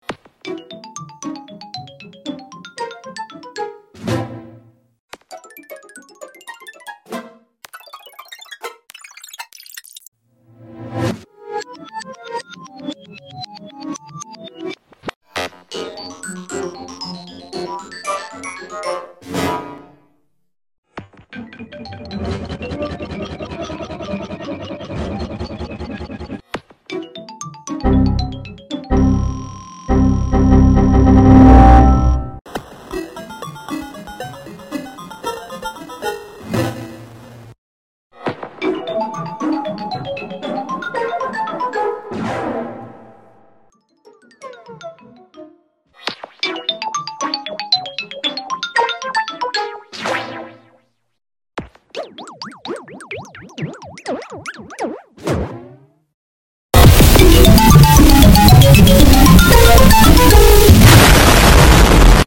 17 GameCube sound variations in sound effects free download
About 17 GameCube sound variations in Mp3 Sound Effect